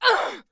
damage_1.wav